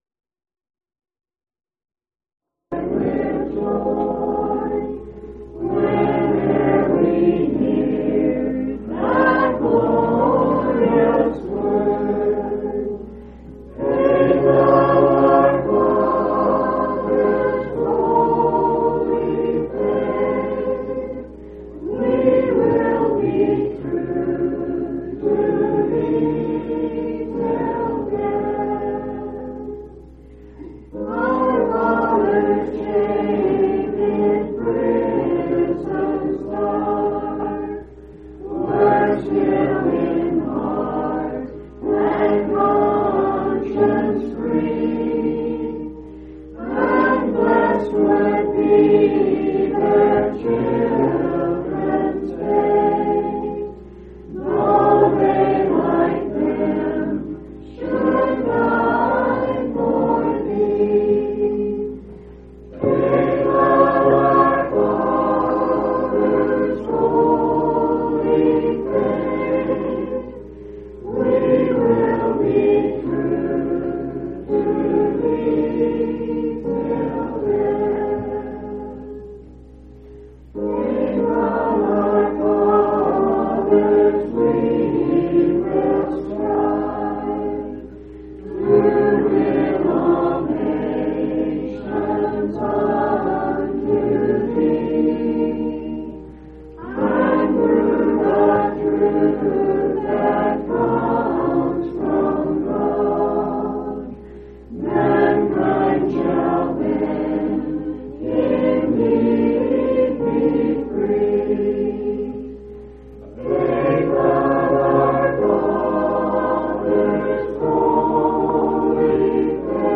6/14/1997 Location: Colorado Reunion Event